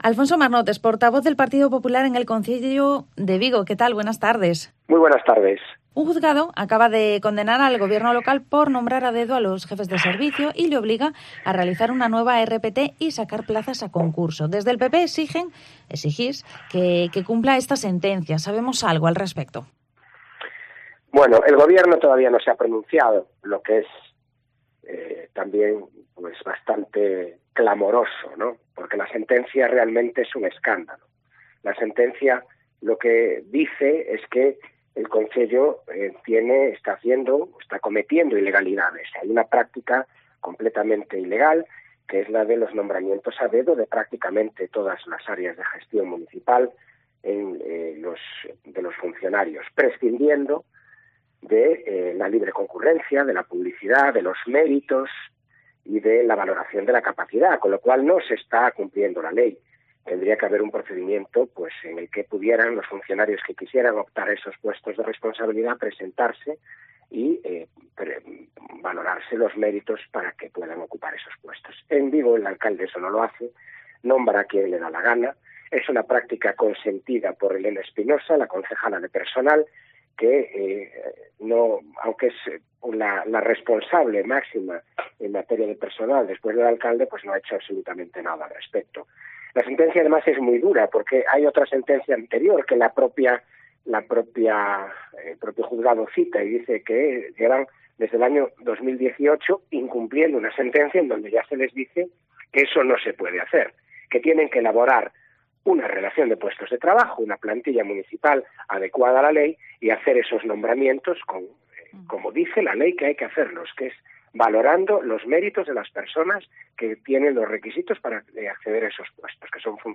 ENTREVISTA
Hablamos con el portavoz del Partido Popular en el Concello de Vigo, Alfonso Marnotes, sobre la reciente sentencia que obliga al Gobierno local a realizar una nueva RPT y a sacar plazas a concurso. También sobre la Oferta de Empleo Público (OPE) que acaban de anunciar en el Concello de Vigo y sobre el dinero del presupuesto 2020 que no se ha gastado.